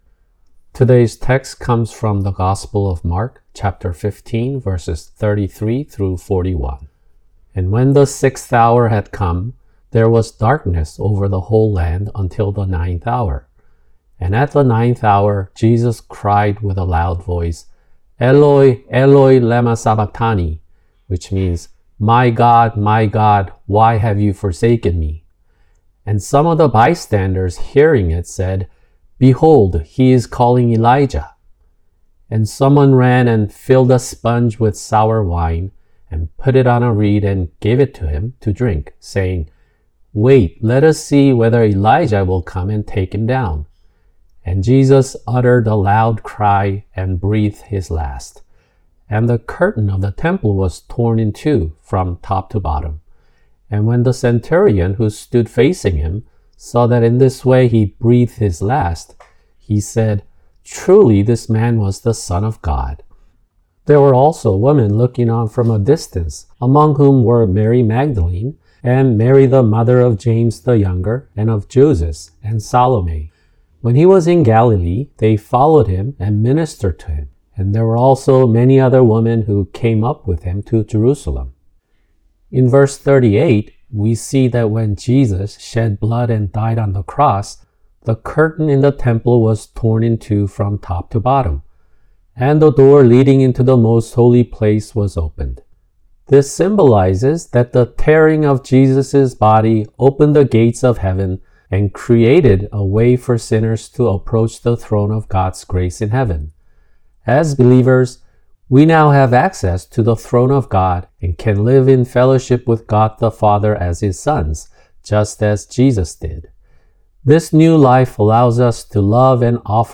[주일 설교] 마가복음(75) 15:33-41
[English Audio Translation] Mark(75) 15:33-41